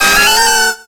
Cri de Girafarig dans Pokémon X et Y.